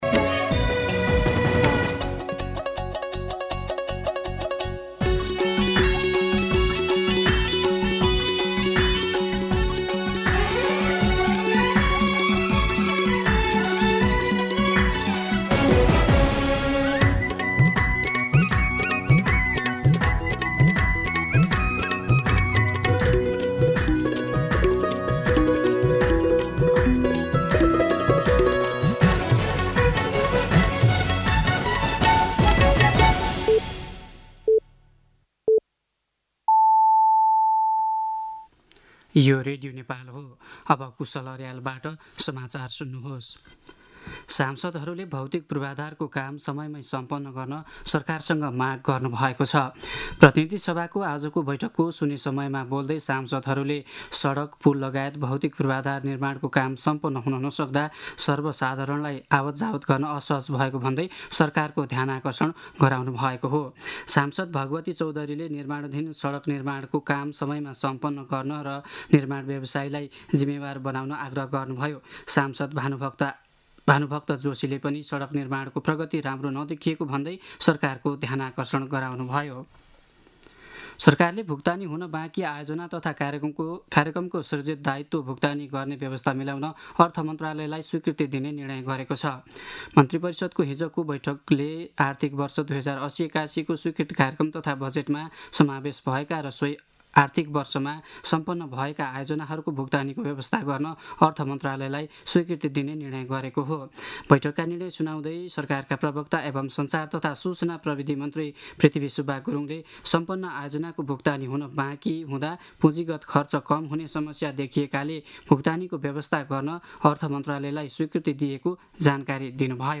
दिउँसो ४ बजेको नेपाली समाचार : १४ फागुन , २०८१